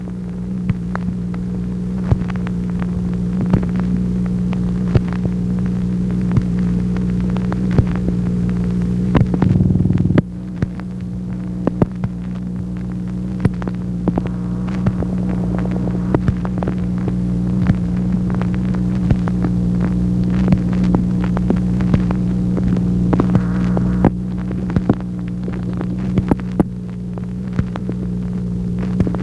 MACHINE NOISE, January 22, 1964
Secret White House Tapes | Lyndon B. Johnson Presidency